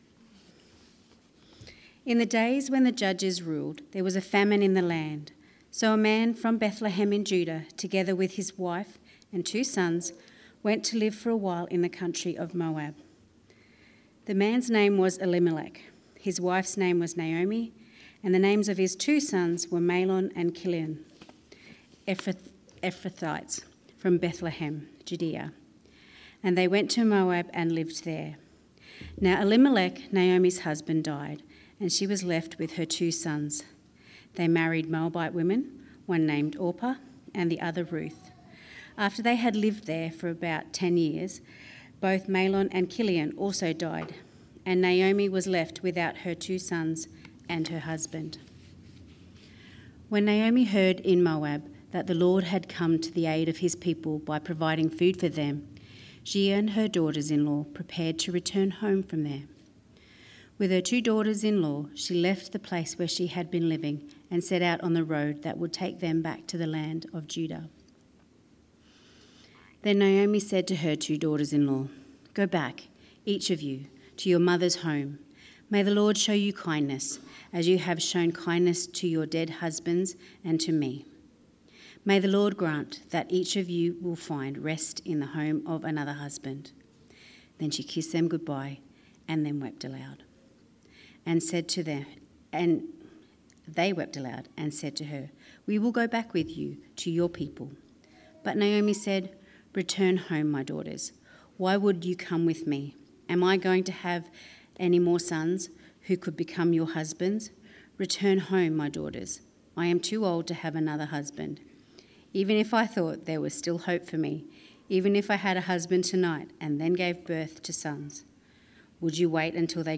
Sermons – Cairns Presbyterian Church